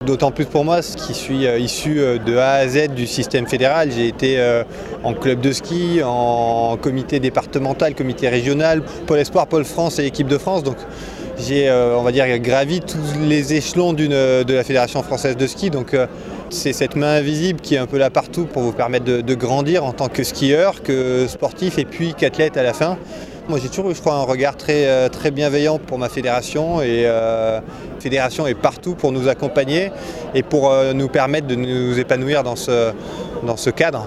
La Fédération française de ski a célébré hier soir son centenaire au Podium de Poisy.
Martin Fourcade était au micro d'ODS radio.